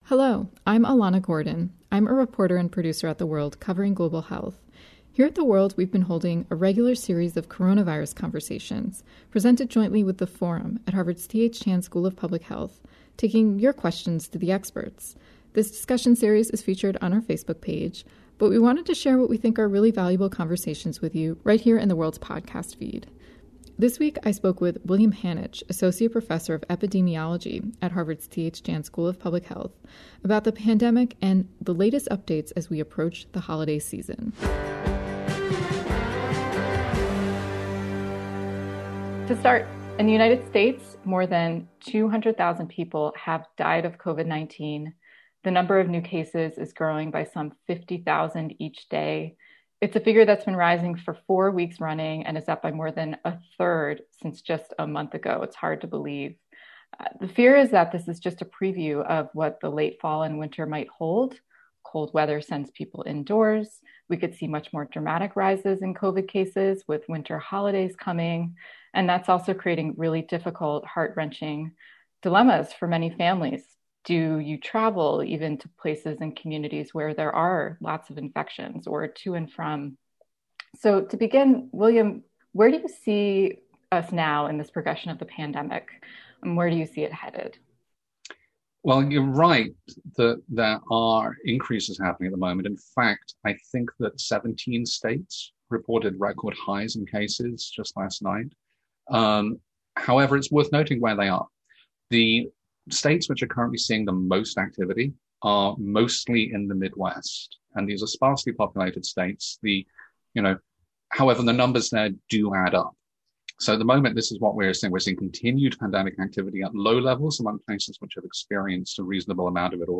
moderated a discussion